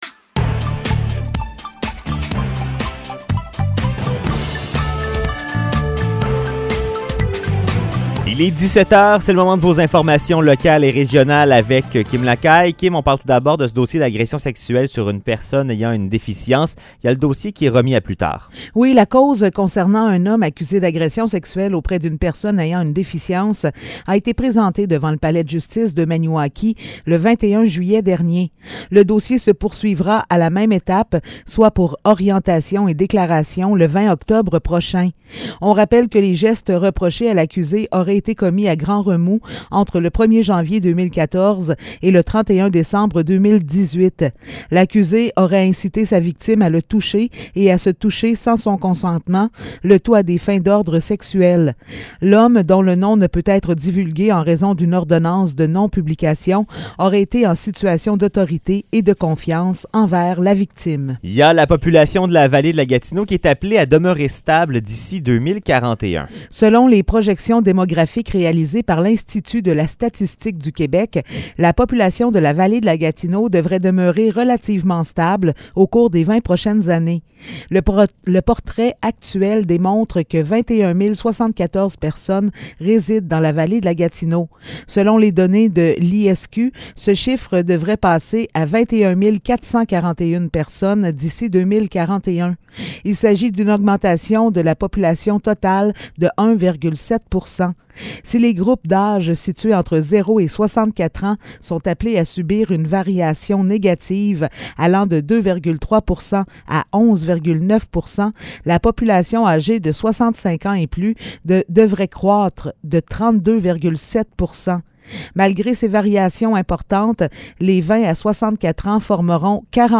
Nouvelles locales – 1er août 2022 – 17 h